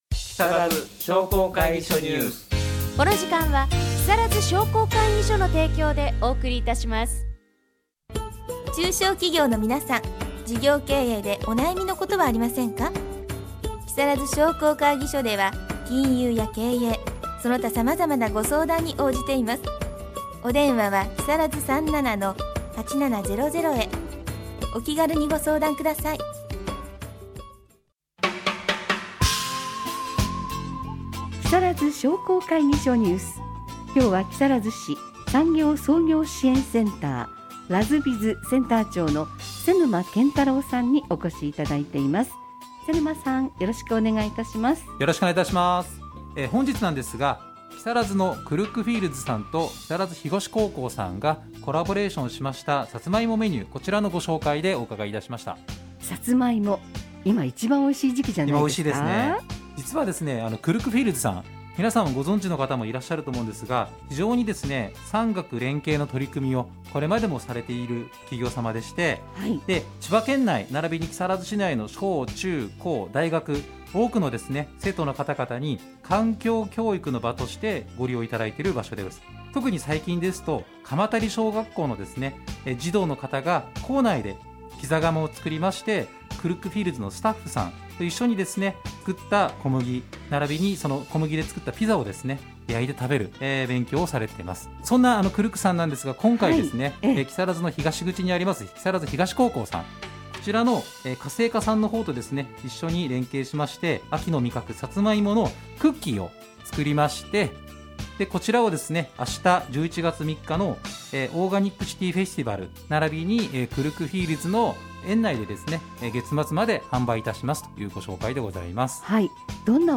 かずさエフエム「木更津商工会議所ニュース」11/2放送分 出演：株式会社KURKKU FIELDS様